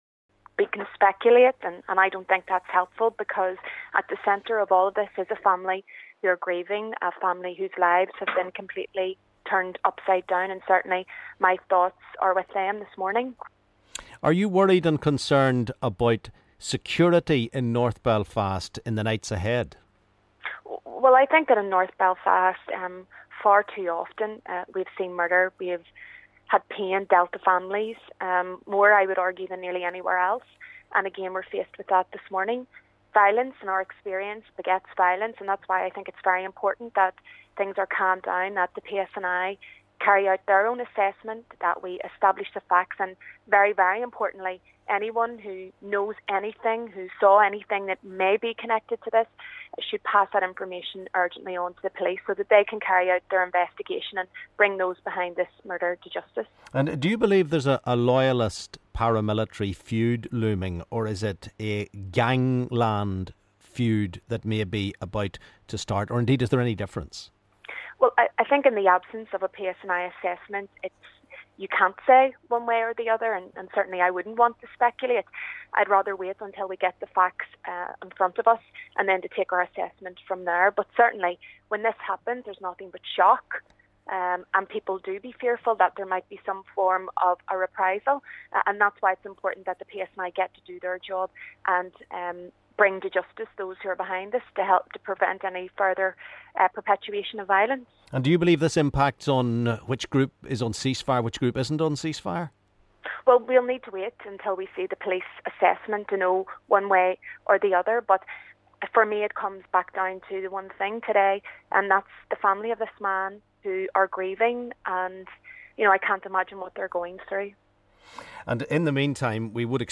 LISTEN: SDLP MLA gives her reaction to the murder of a man in north Belfast.